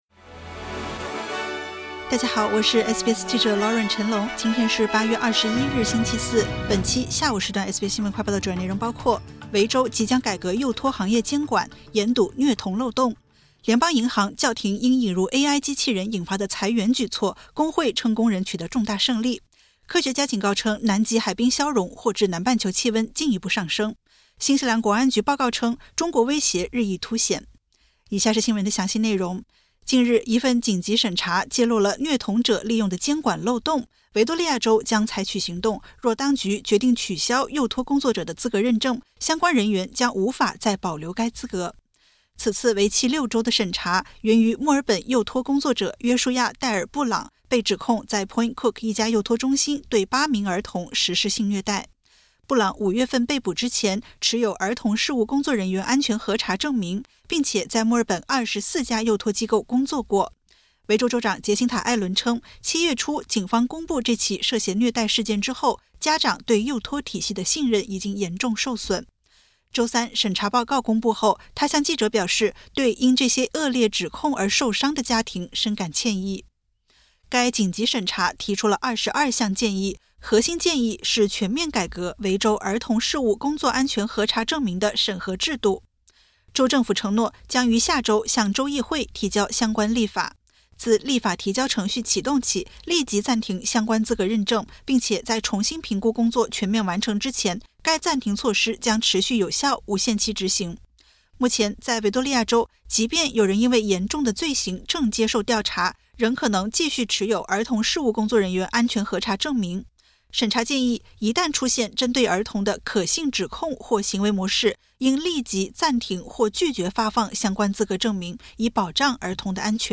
【SBS新闻快报】 维州即将改革幼托行业监管 严堵虐童漏洞